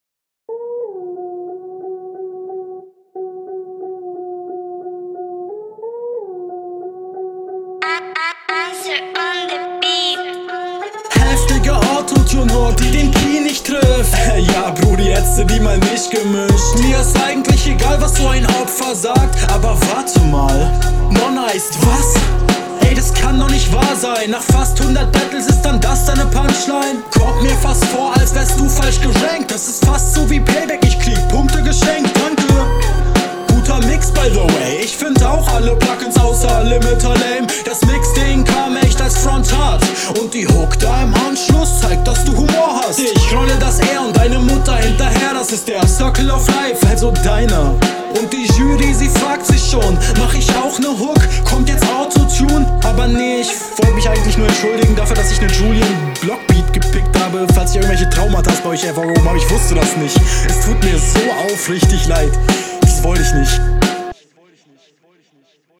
Flow stabil, Mix meh, Punches sehr strong, Reime ok